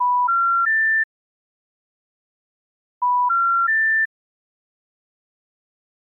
Zapateller es una aplicación del dialplan de Asterisk que genera un tri-tono (tono SIT )… un tipo de tono telefónico que debe sonar cuando alguien llama a un número de teléfono que no está en funcionamiento.
Ejemplo de tri-tono (SIT tone)